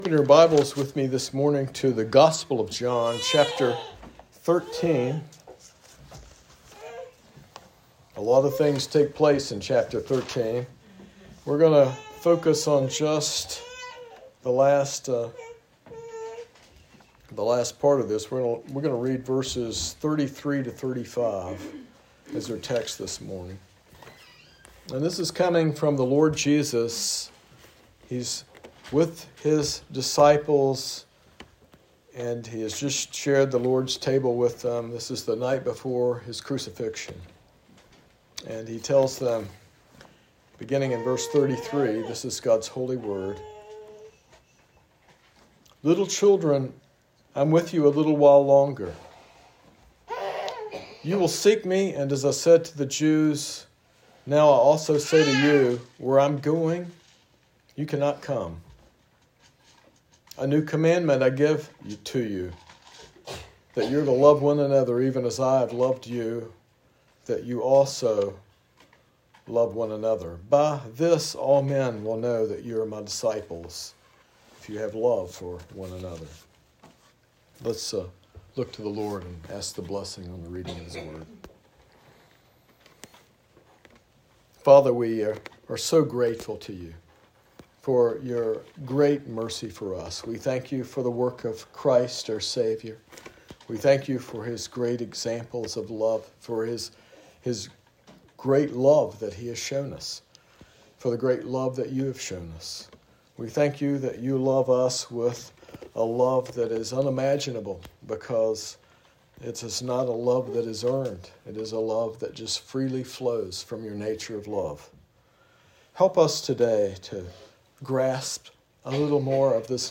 This sermon explores Jesus’ new commandment to love one another as he has loved us, using the analogy of a ‘real ID’ to signify true discipleship. It challenges listeners to move beyond self-centeredness and cultural definitions of love, embracing the sacrificial and transformative love exemplified by Christ.